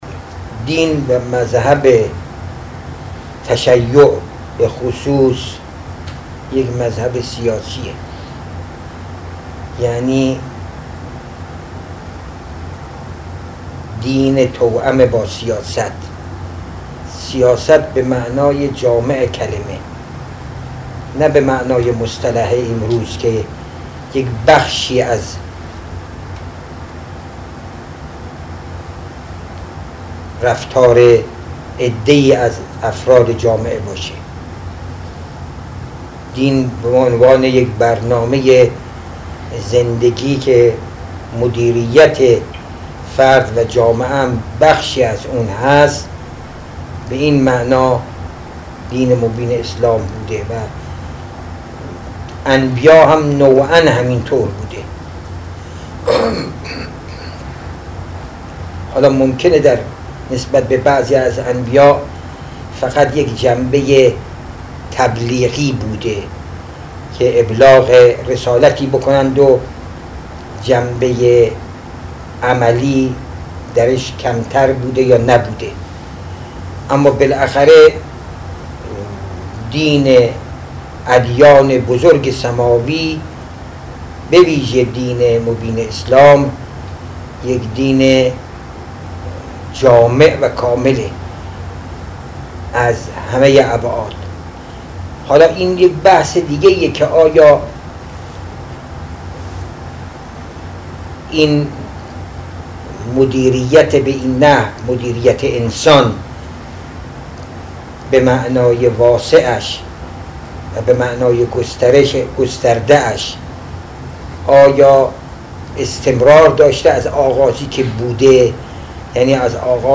به گزارش خبرنگار سیاسی خبرگزاری رسا، آیت الله سید علی حسینی اشکوری استاد درس خارج حوزه علمیه قم پیش از ظهر امروز در دیدار با جمعی از طلاب و فضلای حوزه های علمیه با بیان این که دین و به ویژه مذهب تشیع سیاسی به معنای جامع کلمه است، گفت: دین به عنوان برنامه زندگی که مدیریت فرد و جامعه هم بخشی از آن است کاملا سیاسی است.